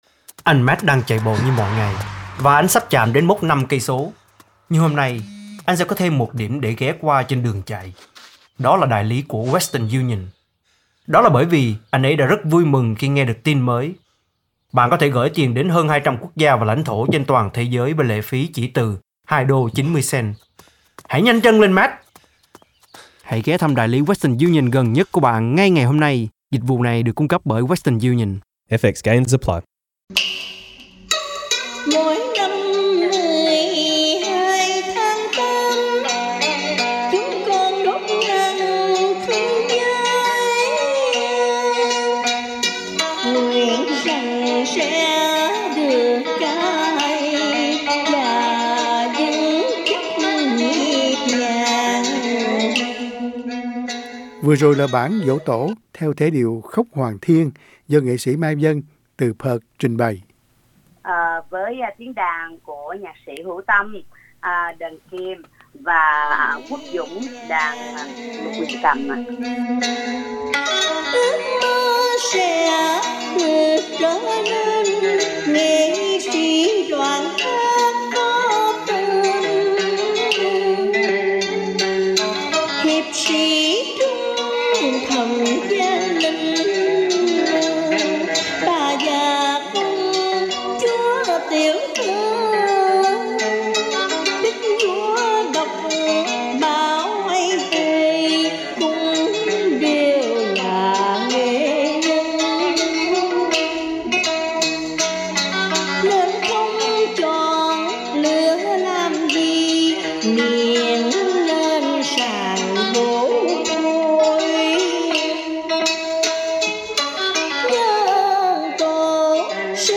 cải lương
đàn kìm
đàn lục huyền cầm